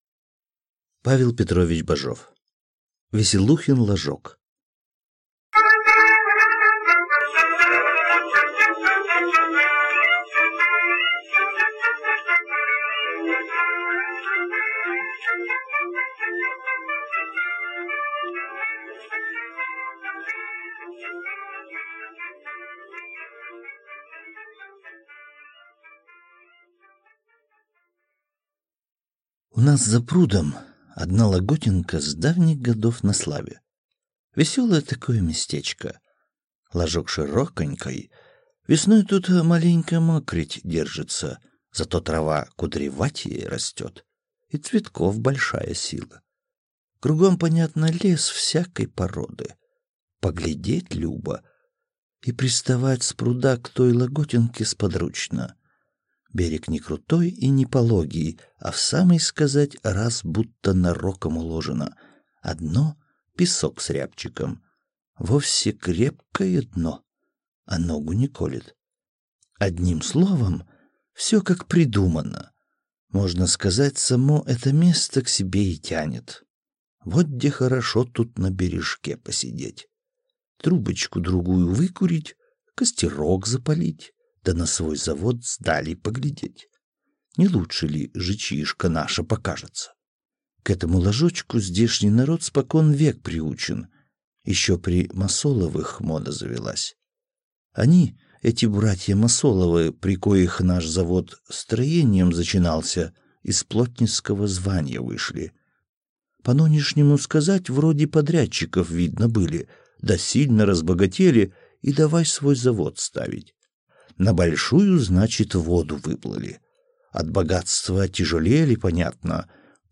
Аудиокнига Веселухин ложок | Библиотека аудиокниг